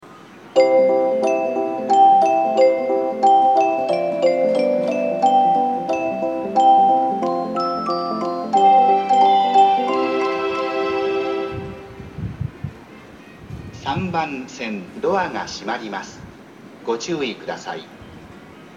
木更津駅　Kisarazu Station ◆スピーカー：BOSE
3番線発車メロディー